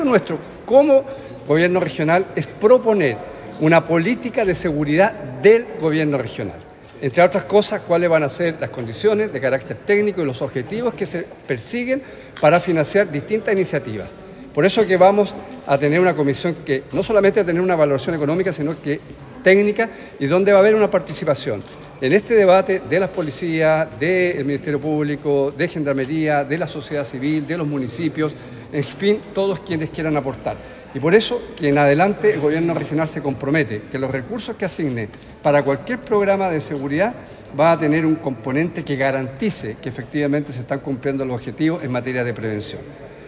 En la reciente sesión plenaria realizada en Puerto Montt, el Gobernador Regional Alejandro Santana destacó la importancia de esta comisión, calificándola como un precedente en la historia de la región, que tiene como objetivo proponer una política de seguridad regional, evaluando no solo el aspecto económico, con participación activa de las policías, la sociedad civil, los municipios y otros actores clave.